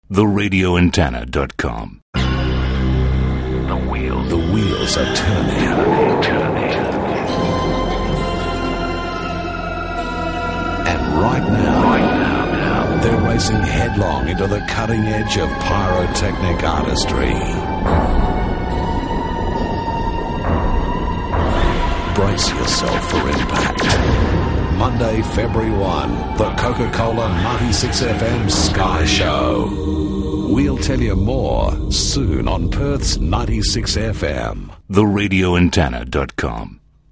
once again with another impressive teaser promo for the event that always goes off